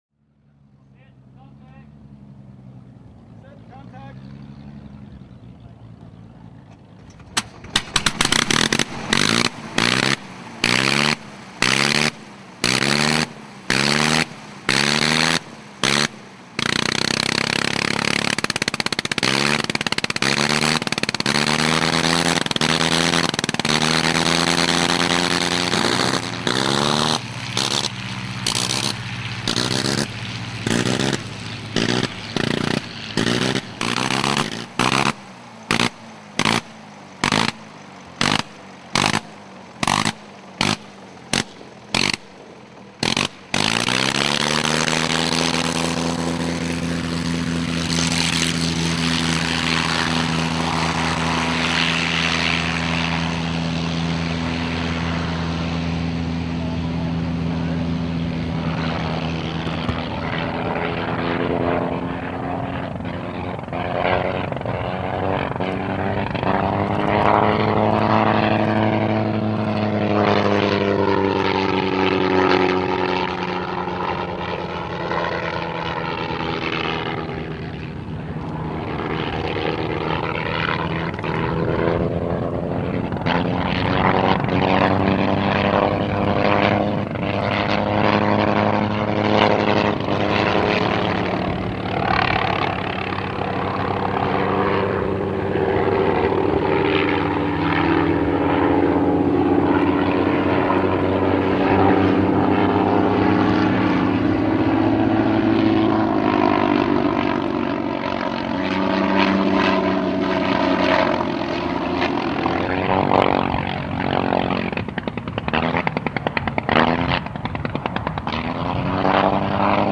WW1 Sopwith Camel With Original Rotary Engine